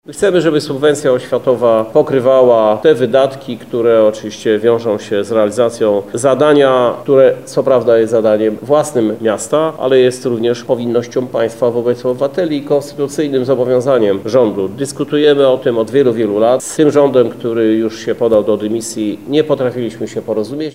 W przyszłym roku miasto do subwencji oświatowej musi dopłacić 240 mln zł, a uwzględniając inne oświatowe inwestycje, łącznie blisko 470 mln zł – przyznaje Krzysztof Żuk, prezydent Lublina: